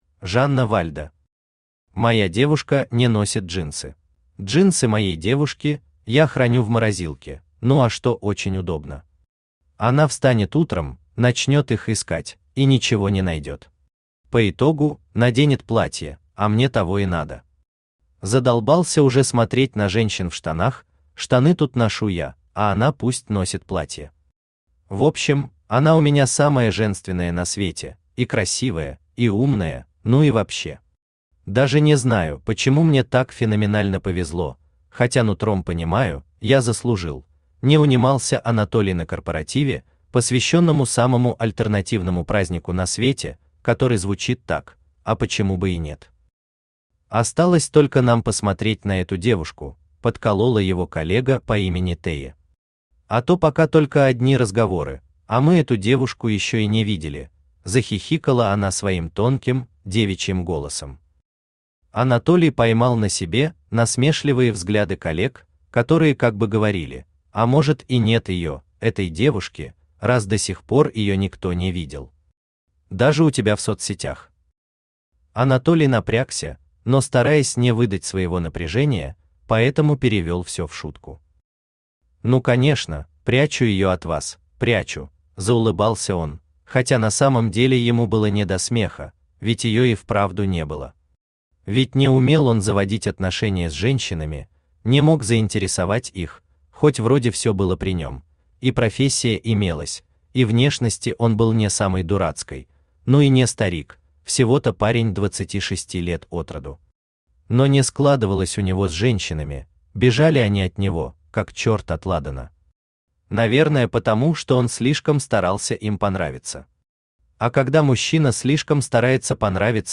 Аудиокнига Моя девушка не носит джинсы | Библиотека аудиокниг
Aудиокнига Моя девушка не носит джинсы Автор Жанна Вальда Читает аудиокнигу Авточтец ЛитРес.